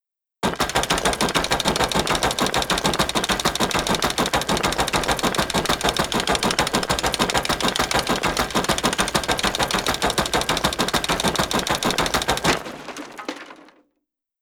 Jackhammer On Stone Wall